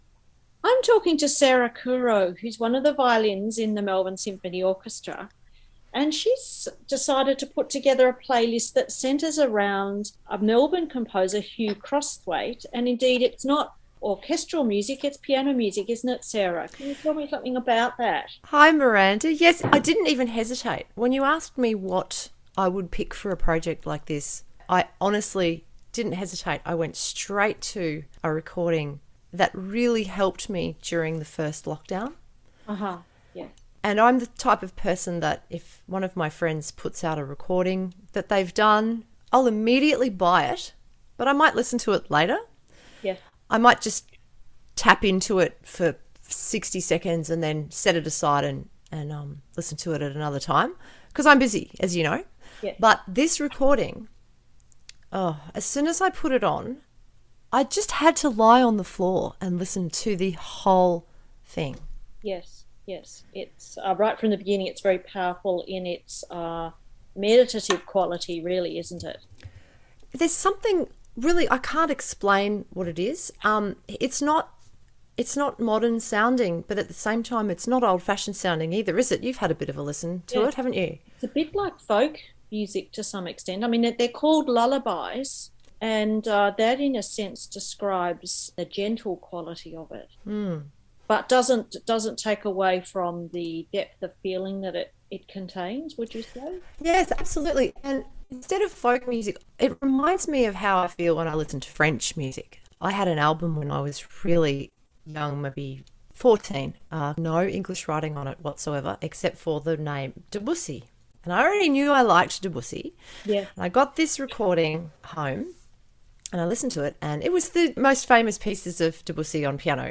Each musician has provided a short introduction to their playlist exploring the meaning the music has for them personally.